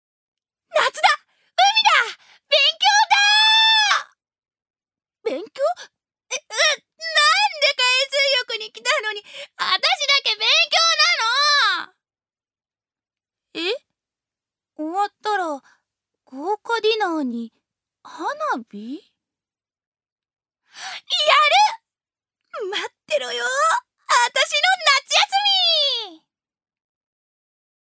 女性タレント
1. セリフ１